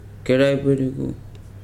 [kɛ́ráù bədùgù] adj. green